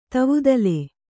♪ tavudale